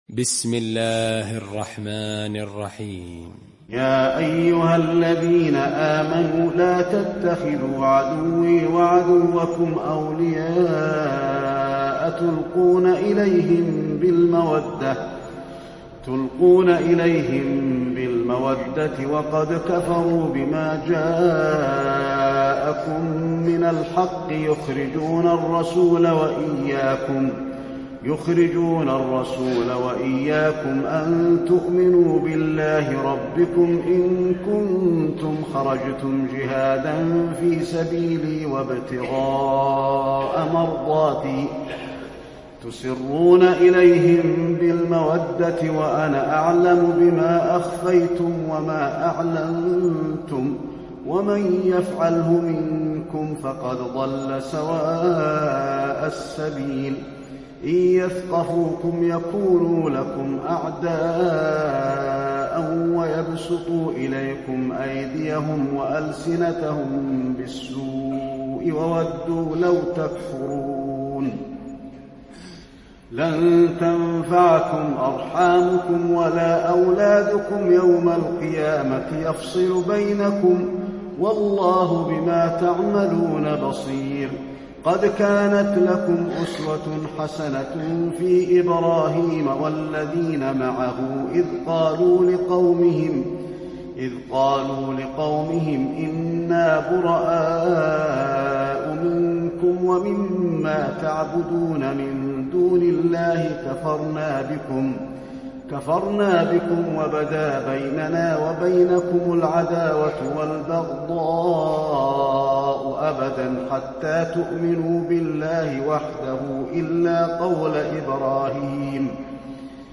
المكان: المسجد النبوي الممتحنة